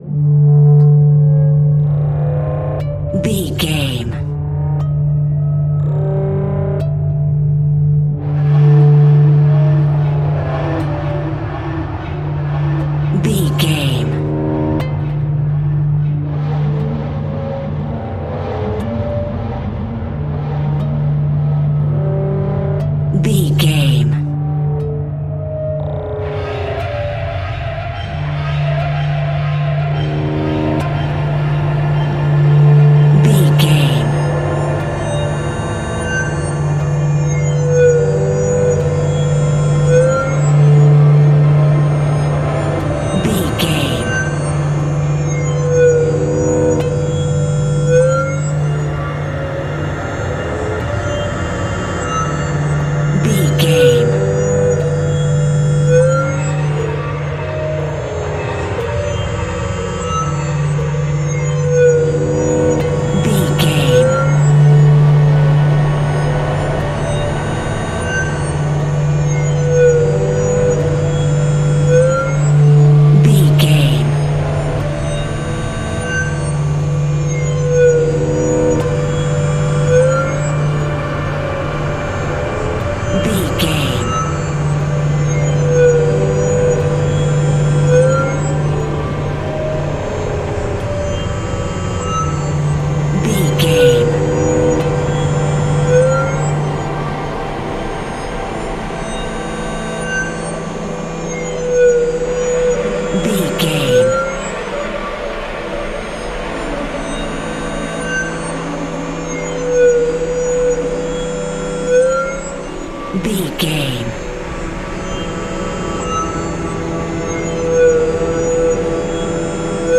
Atonal
E♭
Slow
scary
tension
ominous
dark
suspense
eerie
synthesiser
keyboards
ambience
pads
eletronic